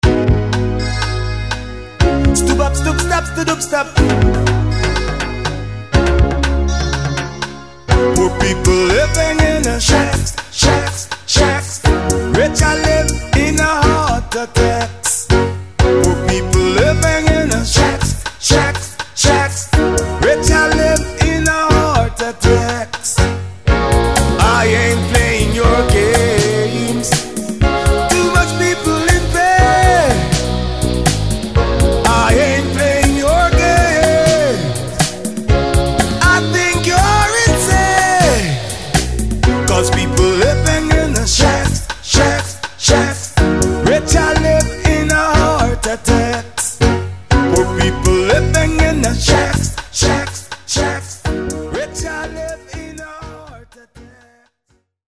With an Eclectic Roots Rock Reggae!